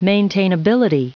Prononciation du mot maintainability en anglais (fichier audio)
Prononciation du mot : maintainability